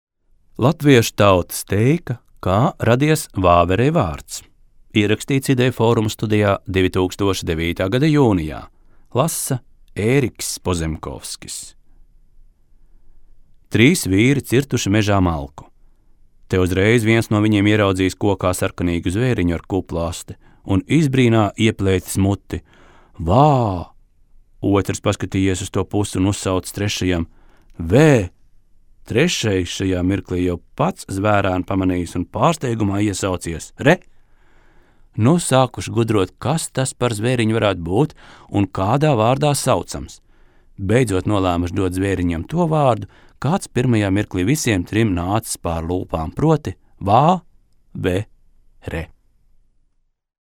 Teikas